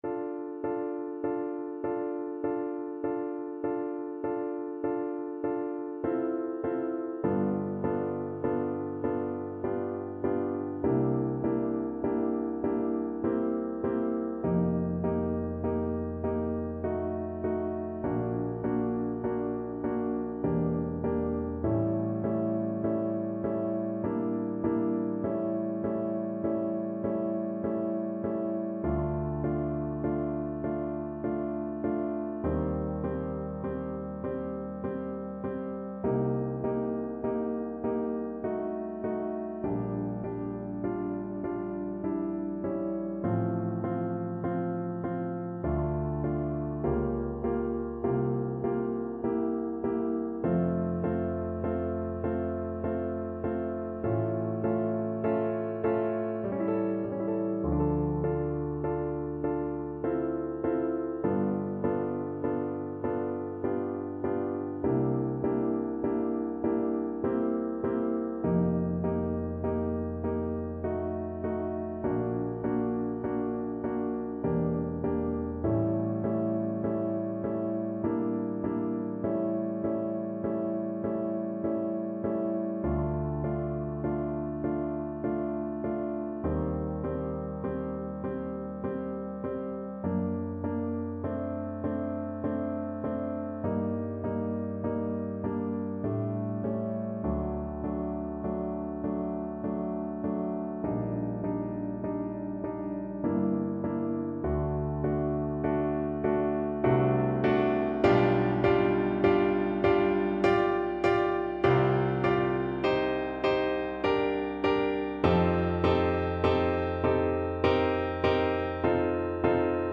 Play (or use space bar on your keyboard) Pause Music Playalong - Piano Accompaniment Playalong Band Accompaniment not yet available transpose reset tempo print settings full screen
French Horn
D minor (Sounding Pitch) A minor (French Horn in F) (View more D minor Music for French Horn )
Andantino = 50 (View more music marked Andantino)
3/4 (View more 3/4 Music)
Classical (View more Classical French Horn Music)